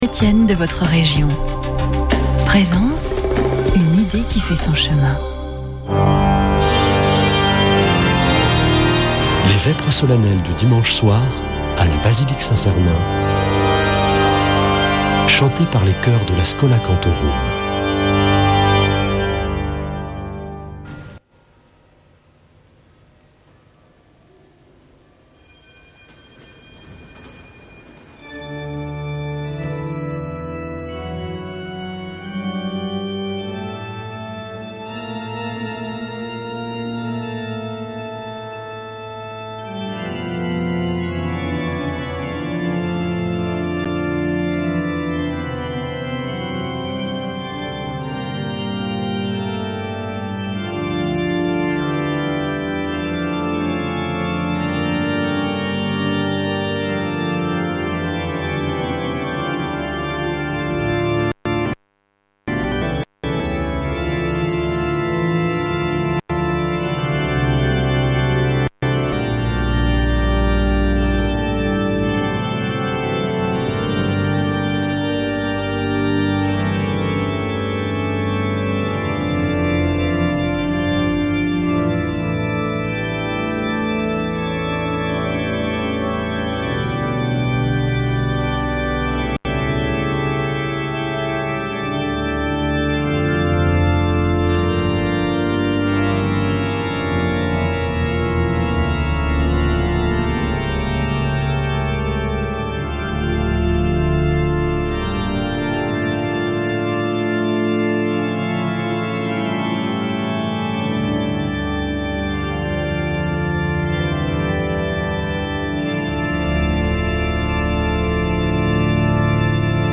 Vêpres de Saint Sernin du 12 oct.